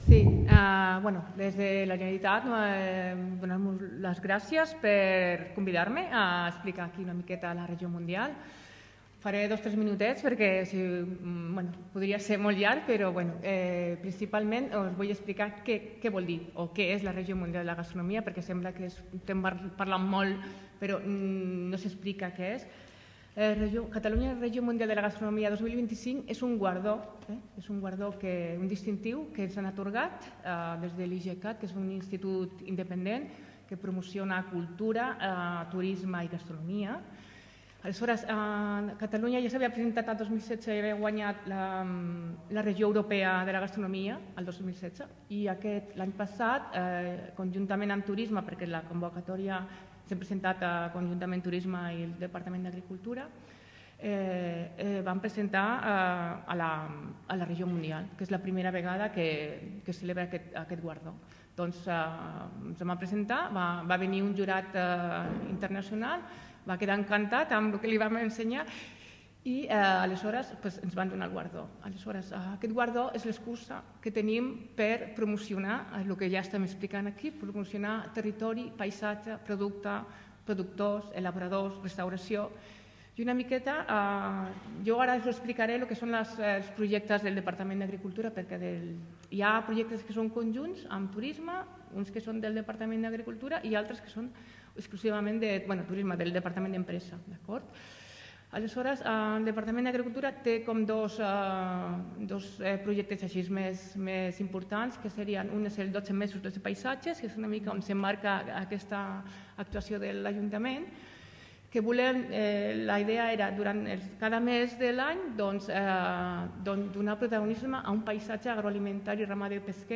L’alcalde del municipi, Carles Motas, ha destacat que aquest projecte, a part d’emmarcar-se en el projecte de Catalunya Regió Mundial de la Gastronomia 2025, reconeix que forma part de l’aposta cultural que s’està fent des del consistori.